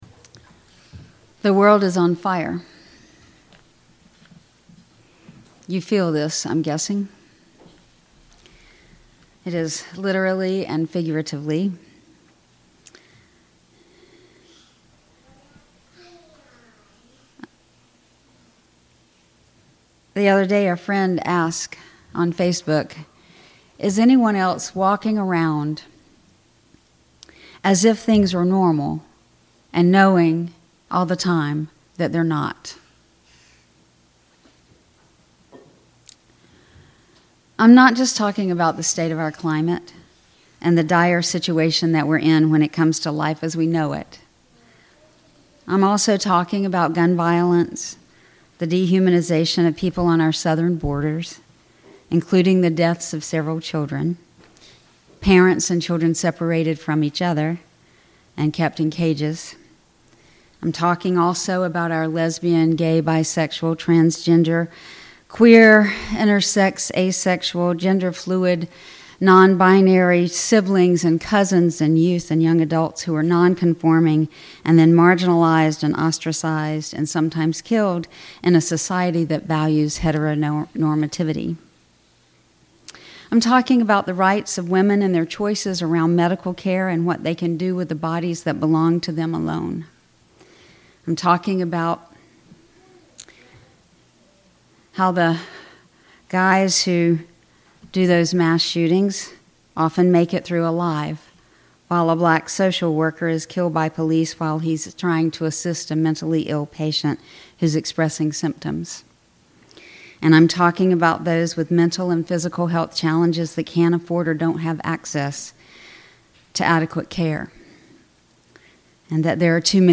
This sermon advocates for a rigorous and active spiritual commitment in response to a world plagued by environmental crisis, systemic violence, and social injustice.